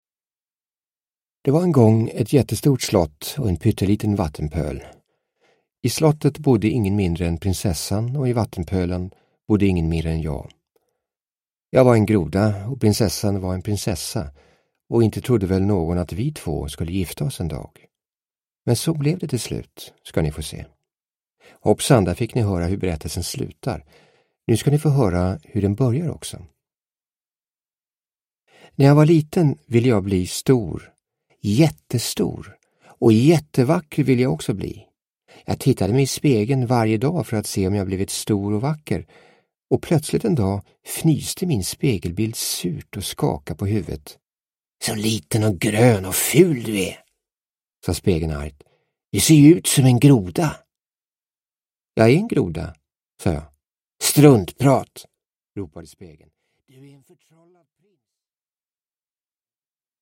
Prins Groda – Ljudbok – Laddas ner